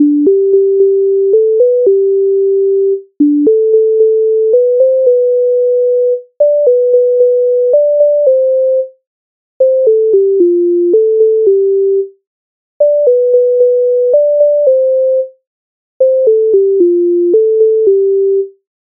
Казала мені мати Українська народна пісня
Ukrainska_narodna_pisnia_Kazala_meni_maty.mp3